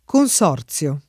consorzio [ kon S0 r ZL o ]